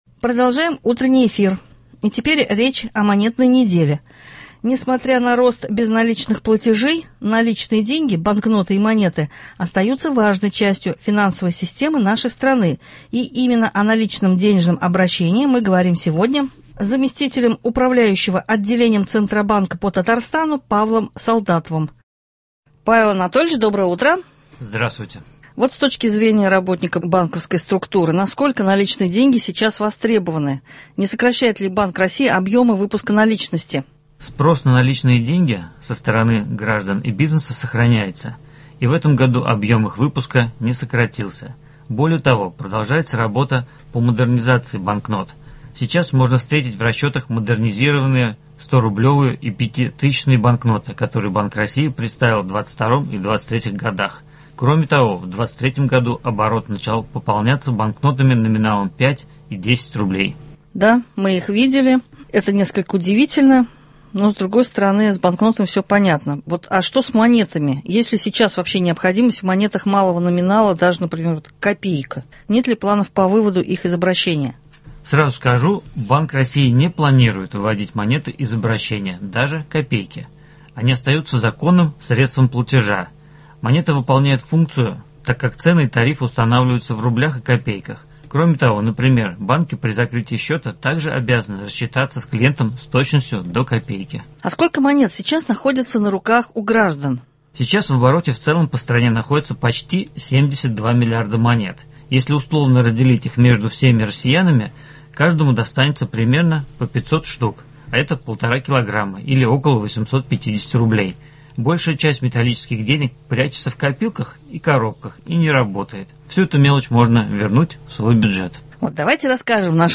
Встречаем «Каравон». Когда пройдет праздник, что интересного ждет гостей — рассказали организаторы.